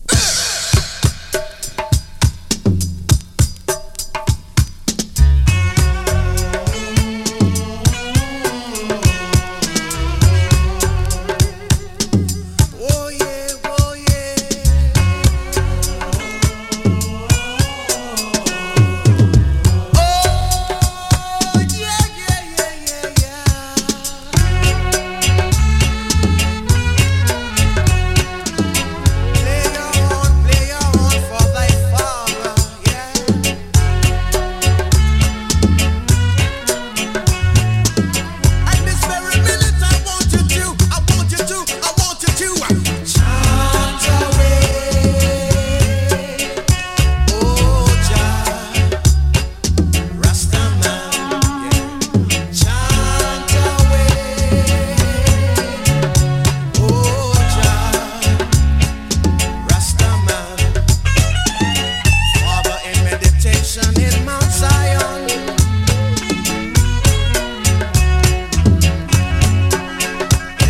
play vocal